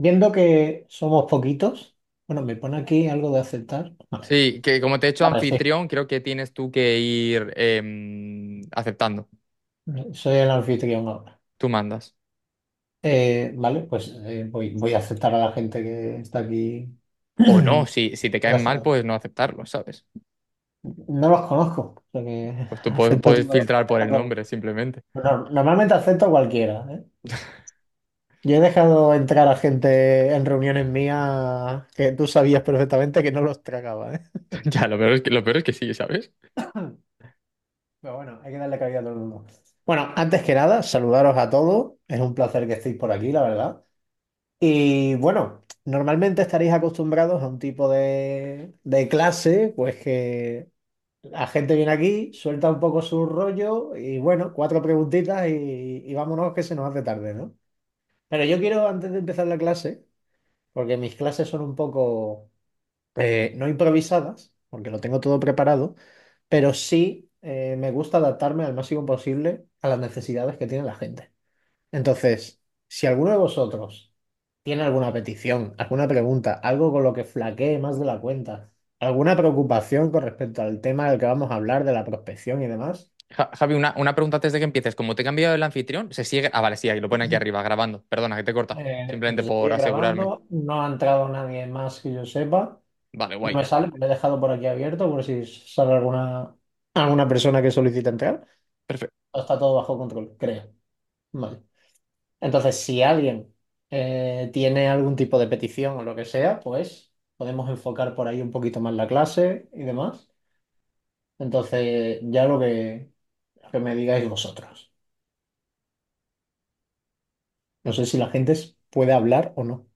Ventas para Copywriters | Masterclass